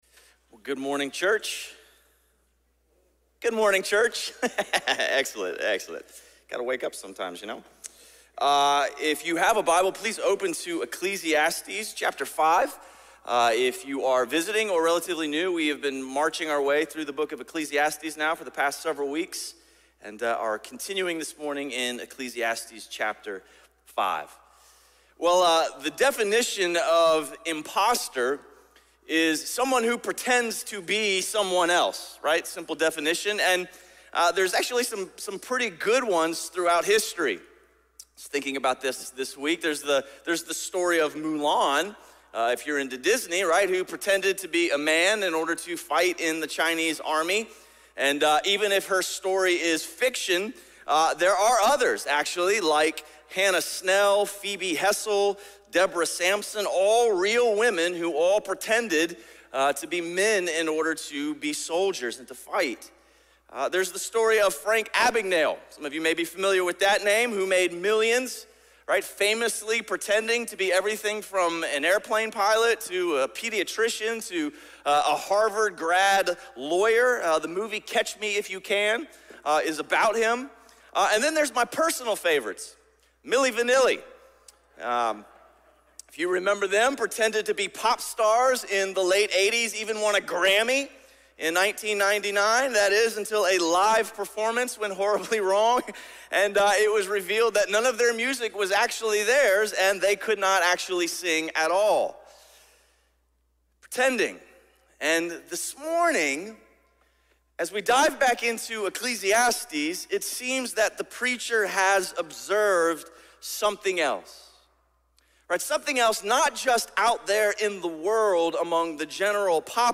A message from the series "New Life in Jesus."
Sermon series through the book of Ecclesiastes.